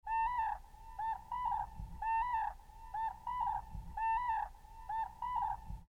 Kevään ensi kurkia Rautalammin Rastunsuolla
Eivätkä nämä upeat linnut onneksi pitäneet kynttilöitään, tai paremminkin trumpettejaan, vakan alla, vaan useaan otteeseen päästivät komeita ja kaikuvia ryhmähuutoja.
Valitettavasti vain tuuli oli illan kuluessa muuttunut puuskittaiseksi, ja tuulenpuuskat tekivät kurkien huudon äänittämisen lähes mahdottoksi.